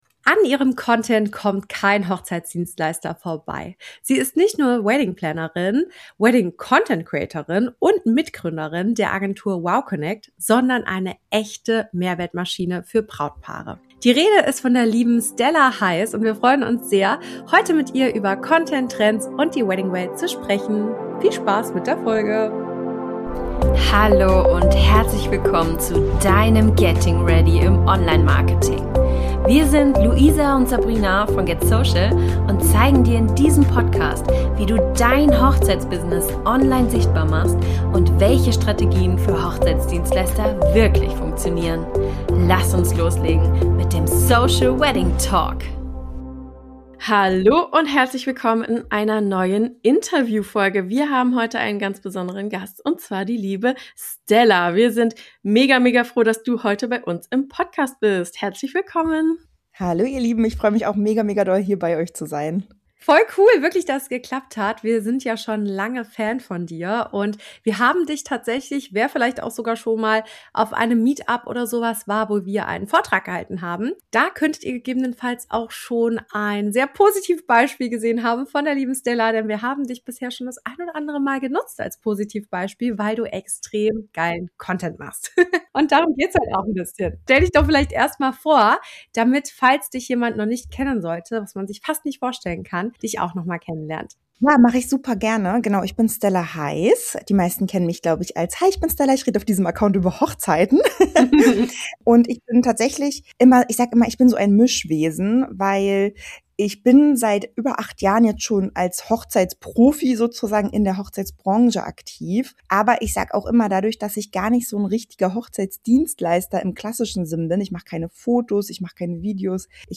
Das erwartet dich in der heutigen Interviewfolge: Warum einfacher Content mehr bringt als perfekter - TikTok vs. Instagram: Wo deine Zielgruppe wirklich unterwegs ist - Warum Reichweite nichts mit Followern zu tun hat - Wie du mit klarer Meinung & Authentizität sichtbar wirst - Der einfache Content-Framework, der wirklich funktioniert Wenn du als Hochzeitsdienstleister endlich mehr Anfragen über Social Media gewinnen willst:  Folge unserem Podcast  Teile die Folge mit jemandem aus der Hochzeitsbranche  Und starte noch heute mit deinem ersten Video!